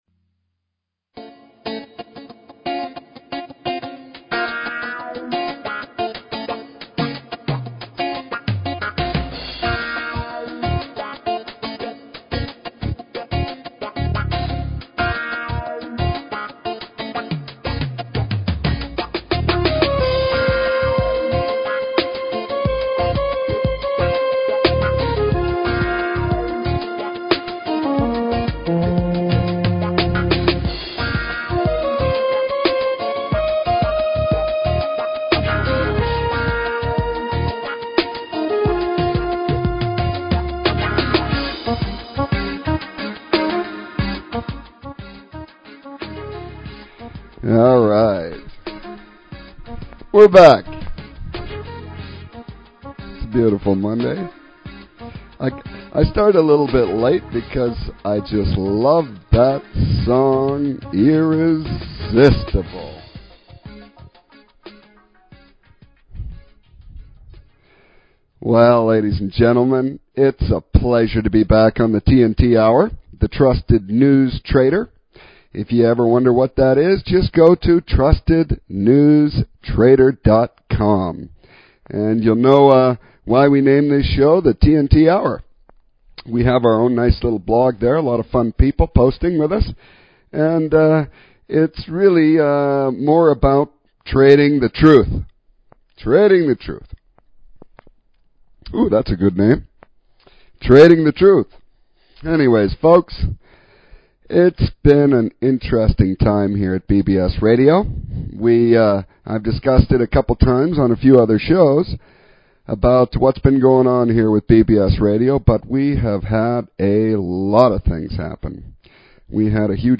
Talk Show Episode, Audio Podcast, The_TNT_Hour and Courtesy of BBS Radio on , show guests , about , categorized as